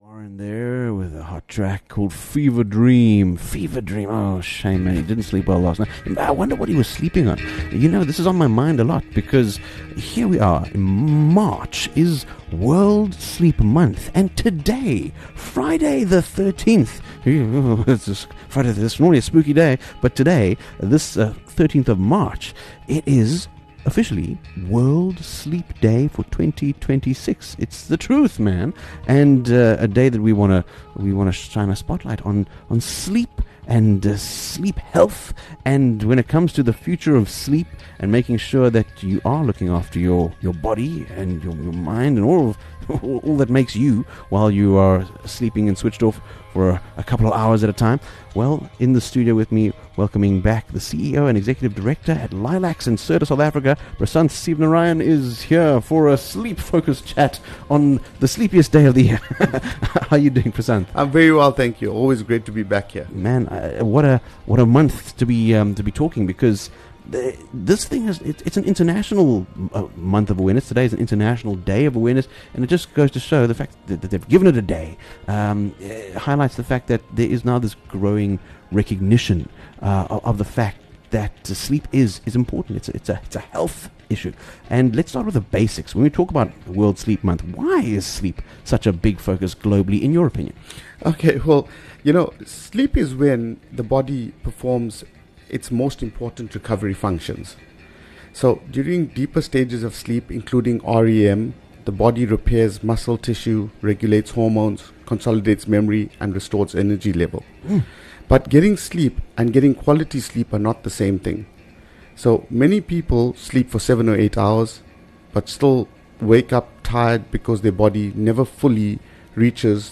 During World Sleep Month, this interview explores how quality sleep depends not just on how a mattress feels but on proper spinal support, with Lylax and Serta sharing insights on sleep recovery, mattress technology, and how to choose the right mattress for better overall health.